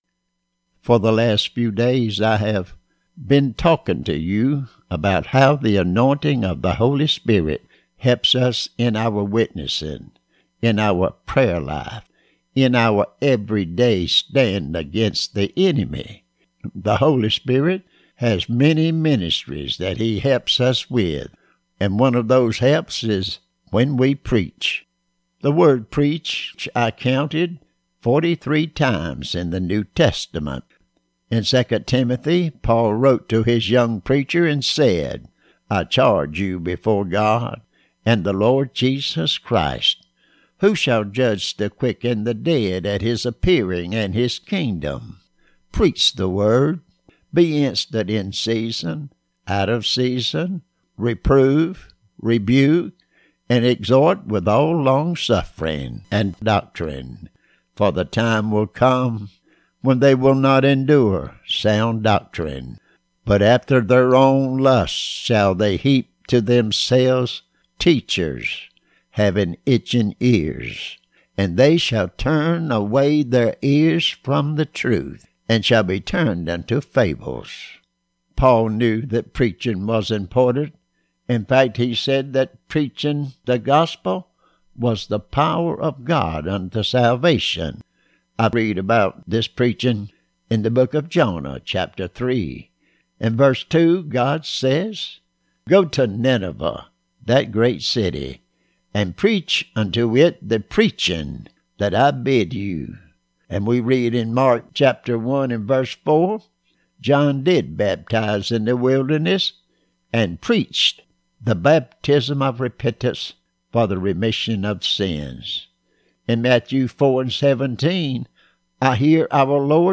Pentecostal Preaching